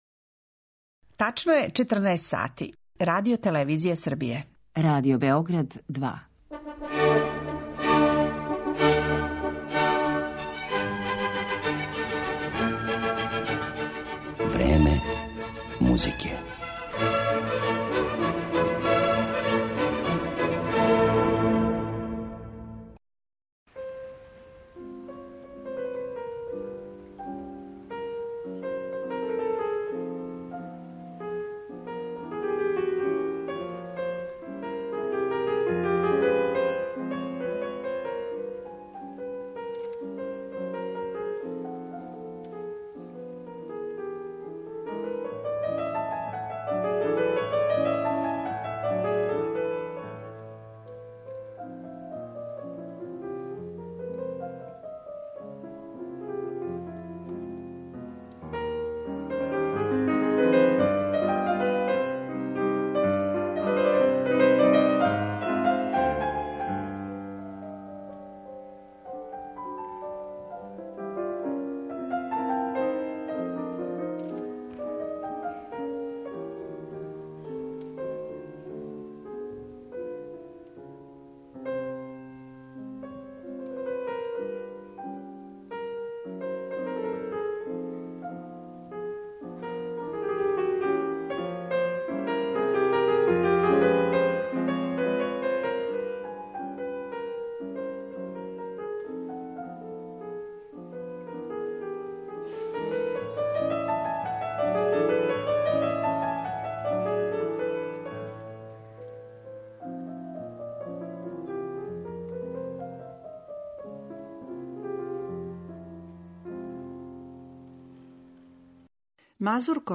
У емисији 'Време музике' слушаћете игре словенских народа које су стилизоване и укључене у најразноврснија дела уметничке музике - од инструменталне свите до опере.